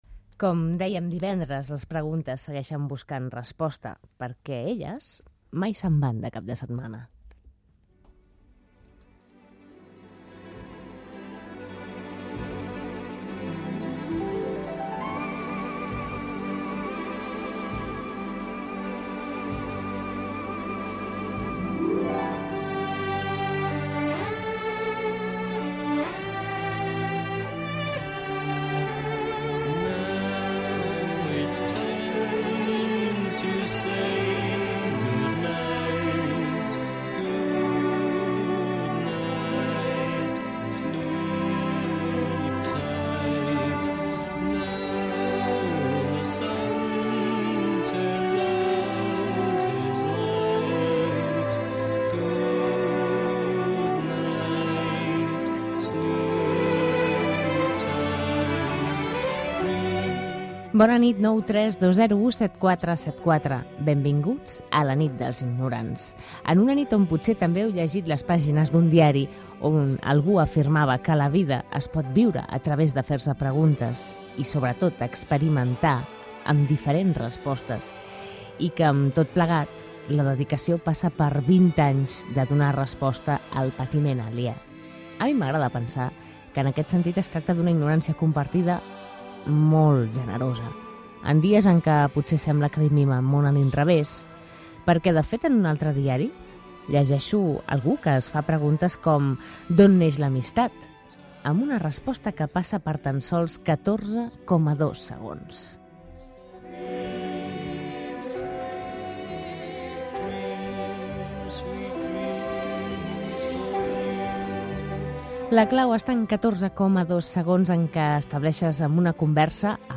Sintonia, telèfon, presentació i primera trucada sobre quan Catalunya era un regne independent i el període de la República Catalana